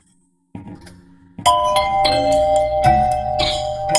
Еще один звонок